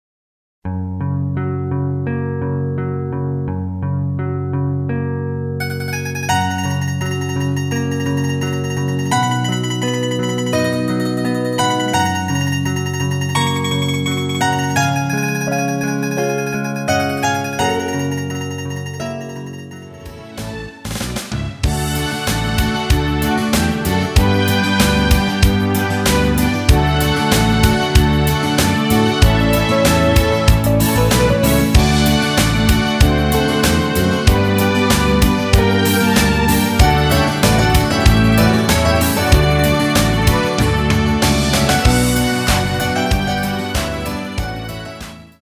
F#
앞부분30초, 뒷부분30초씩 편집해서 올려 드리고 있습니다.